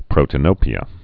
(prōtə-nōpē-ə)